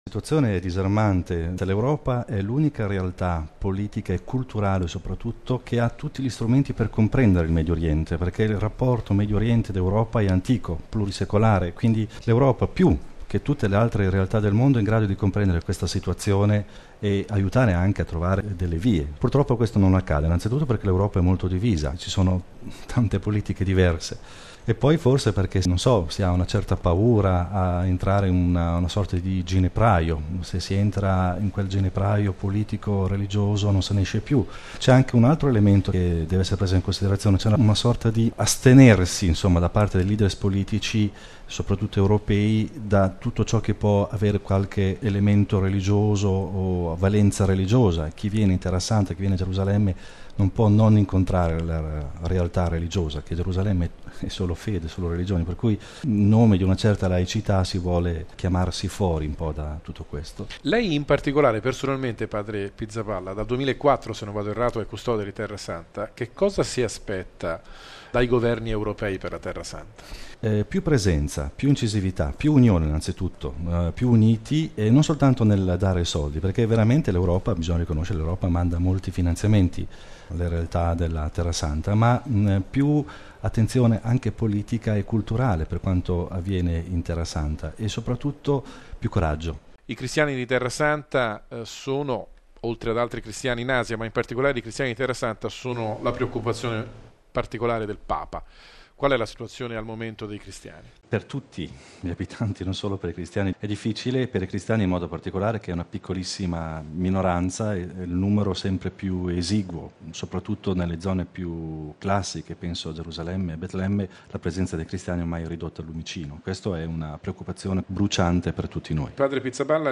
La Terra Santa al centro della terza giornata del Meeting di Rimini